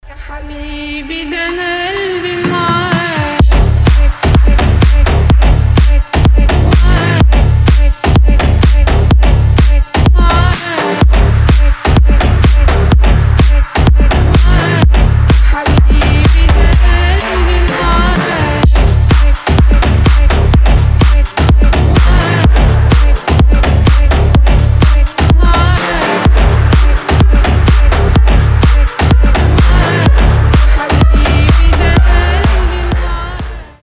• Качество: 320, Stereo
громкие
Electronic
EDM
мощные басы
future house
Bass House
энергичные
арабские
Крутая музыка в стиле bass house, future house